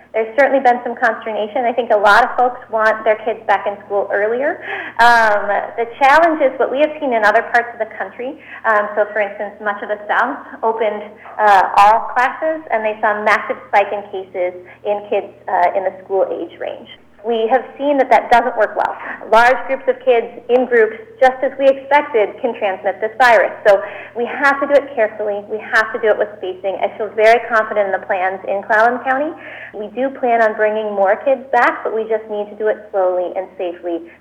PORT ANGELES – At Friday morning’s Covid-19 briefing, Health Officer Dr. Allison Berry Unthank first updated the numbers.